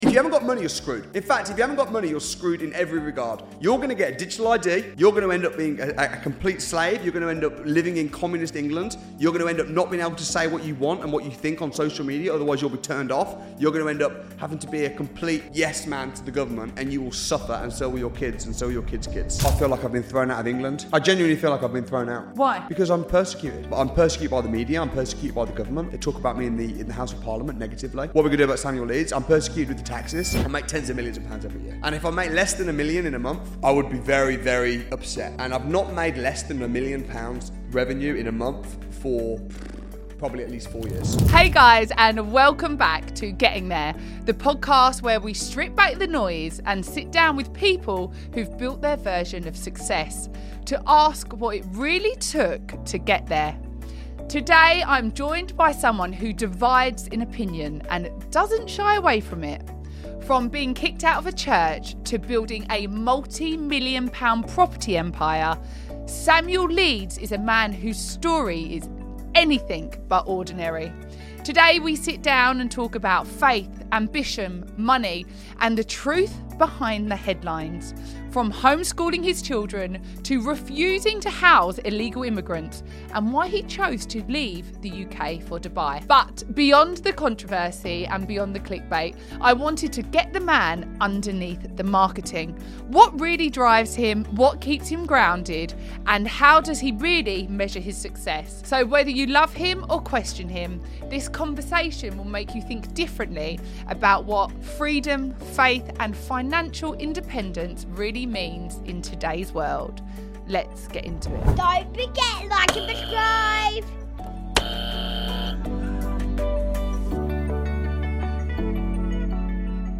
In this powerful and unfiltered conversation